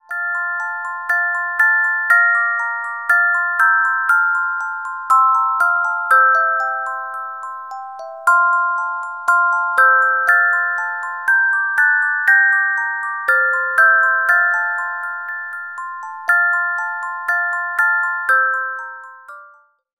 Music Box Melodies柔美音樂盒